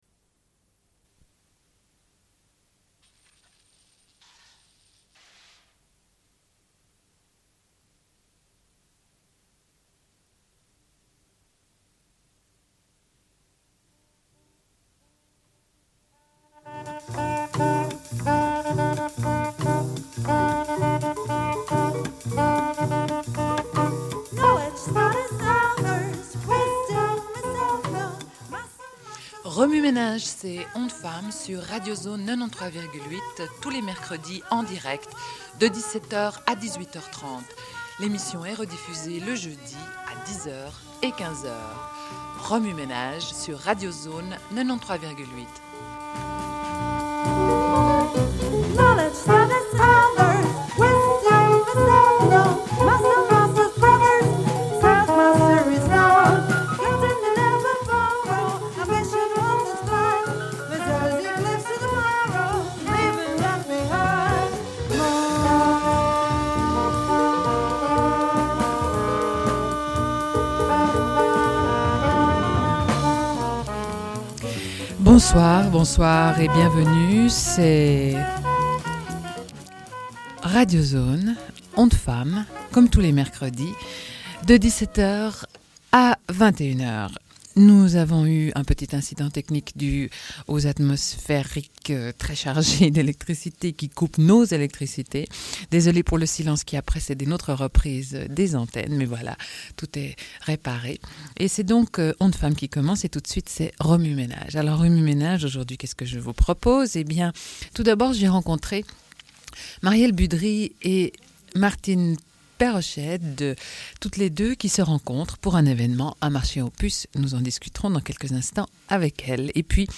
Une cassette audio, face A31:46